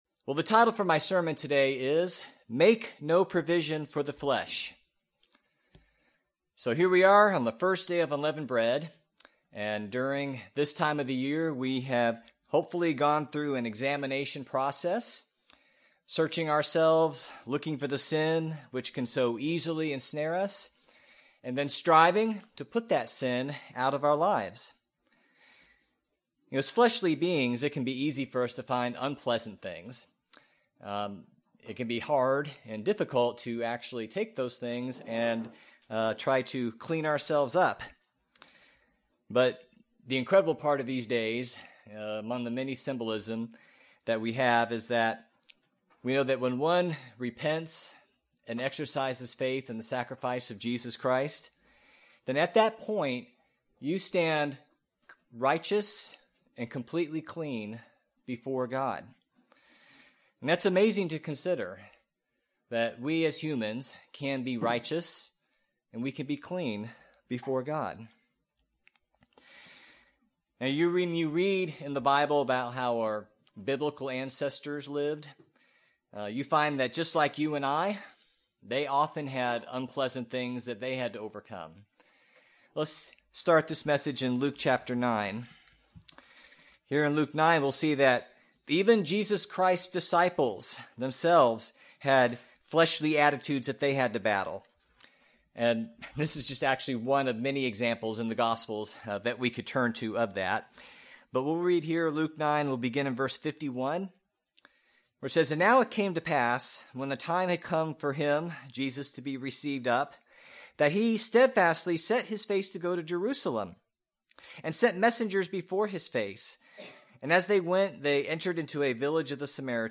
The title for the sermon today is Make No Provision for the Flesh.